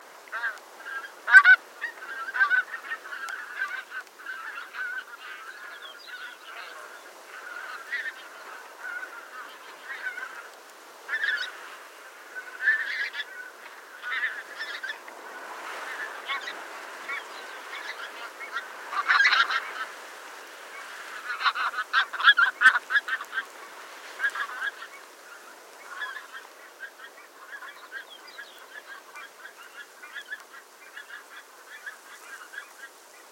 Kortnebbgås – Norsk Polarinstitutt
Låten er et dypt nasalt og støtvis «ang-ank» eller «tjø-tjø-tjøtt» (to- eller trestavet).
NP_LYD_KORTNEBBGAAS-FLUKT.mp3